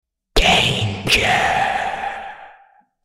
Sound Effect: Horror whisper danger | AI SFX | The AI Voice Generator
Listen to the AI generated sound effect for the prompt: "Horror whisper danger".